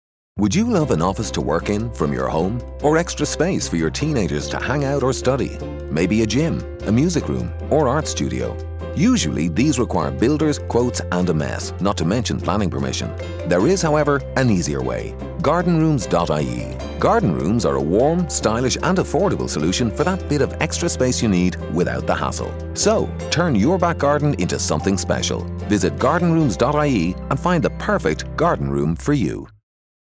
Garden Rooms News Talk Radio Advert | October 2016